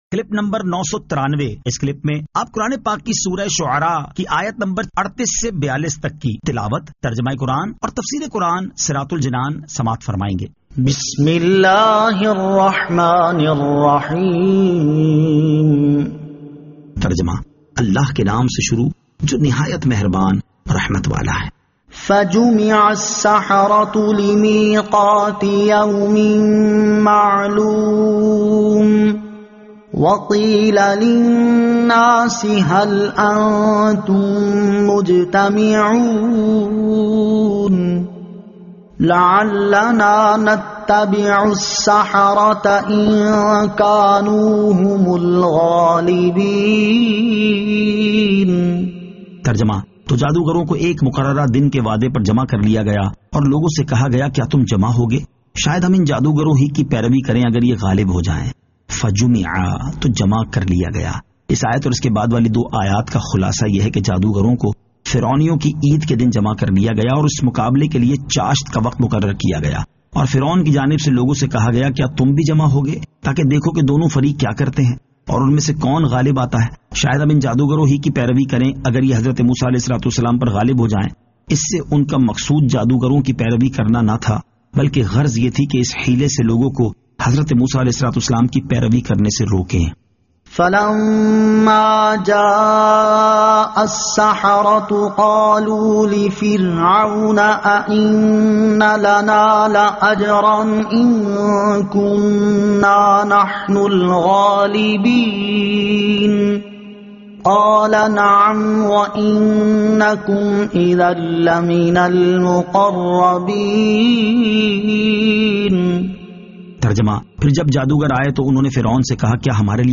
Surah Ash-Shu'ara 38 To 42 Tilawat , Tarjama , Tafseer